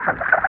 51 NOISE  -R.wav